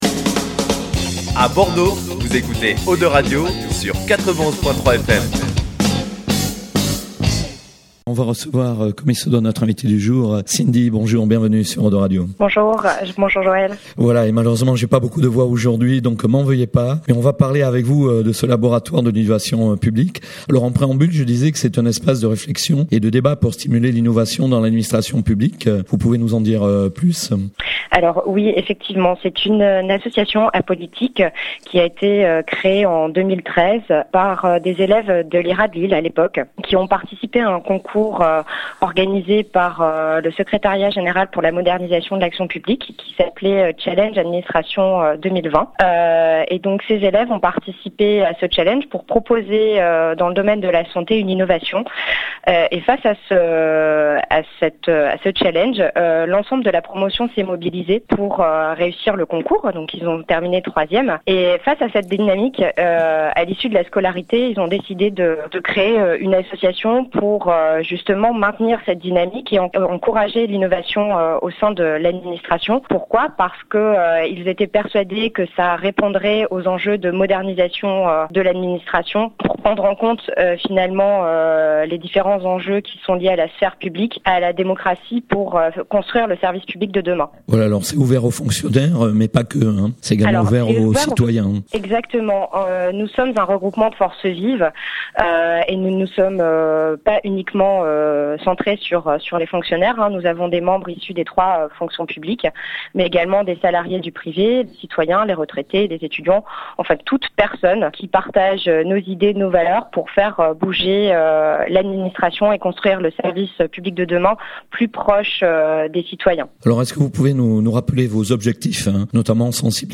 Interview GNIAC